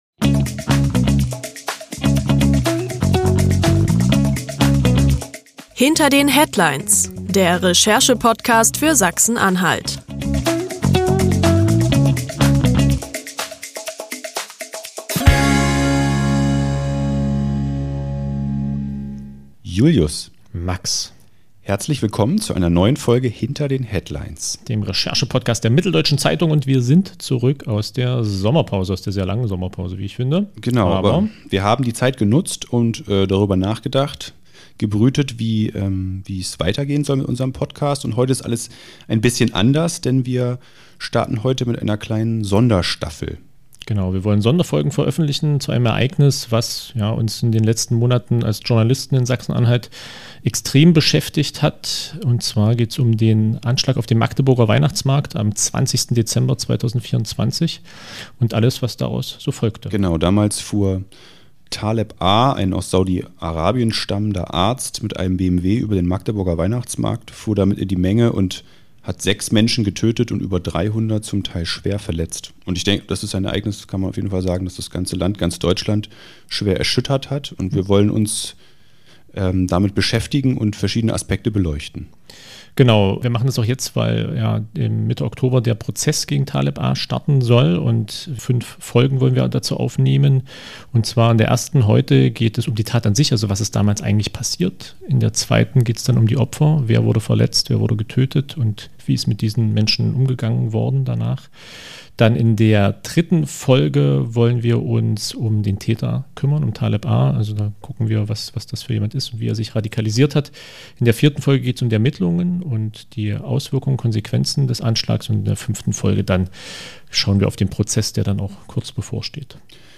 Reporter der Mitteldeutschen Zeitung sowie der Volksstimme aus Magdeburg sprechen dabei gemeinsam über das erschütternde Ereignis und wie es aufgearbeitet wurde.